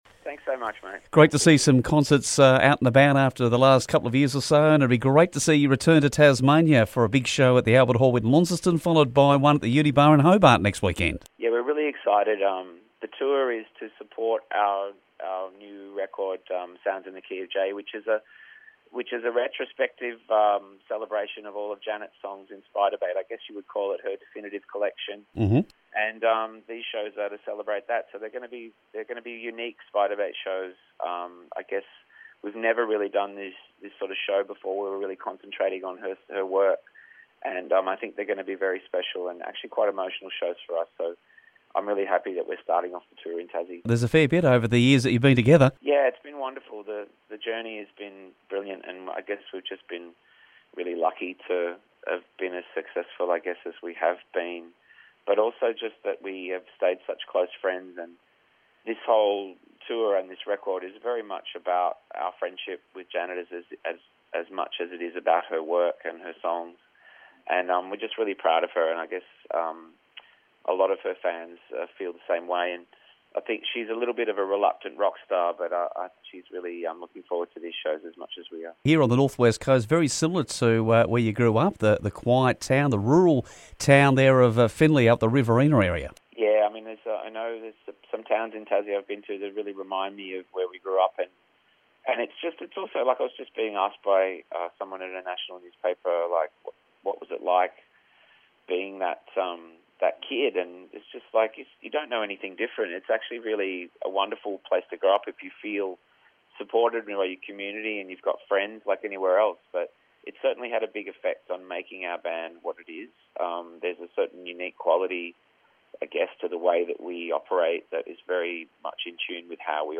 Sounds in the key of J Tour from band Spiderbait hits Launceston at the Albert Hall on Friday June 3 followed by a Hobart gig on Saturday at the Uni Bar and Kram from the band spoke about it.